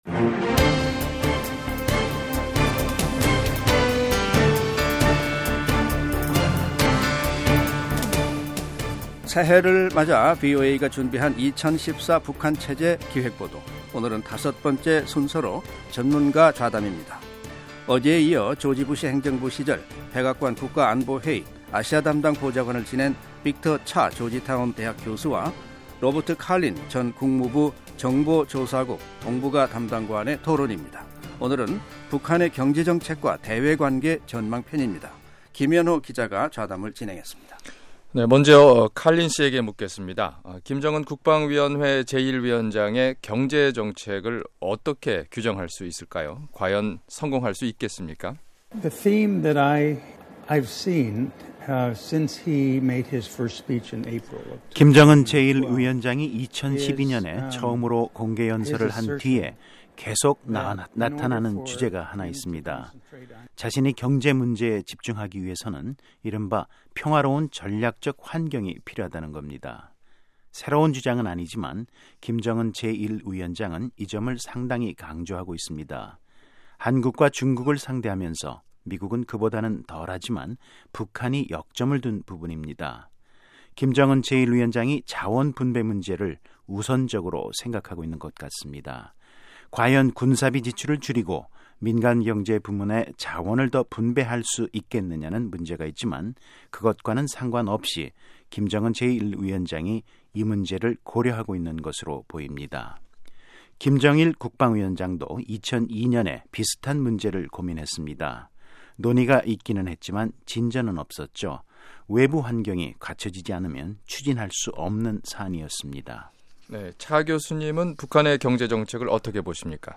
5. 미 전문가 좌담 (2)